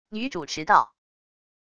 女主持道wav音频